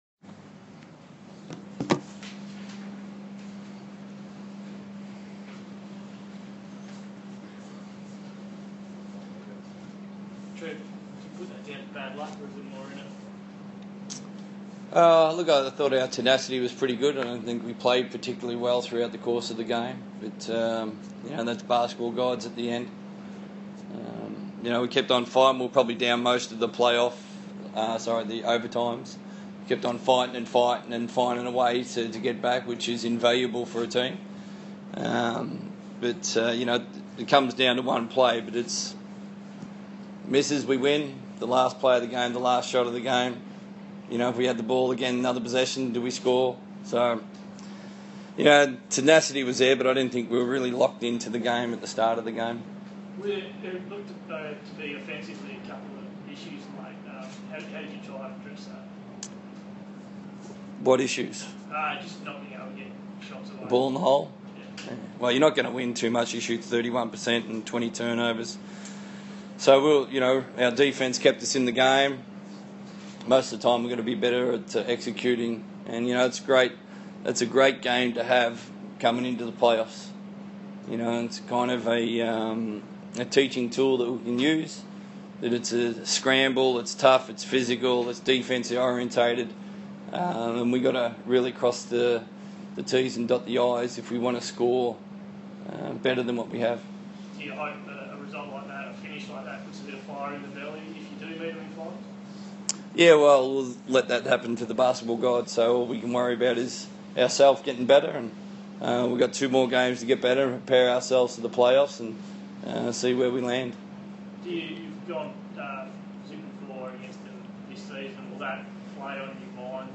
speaks to the media following the Perth Wildcats 87-89 defeat versus the NZ Breakers.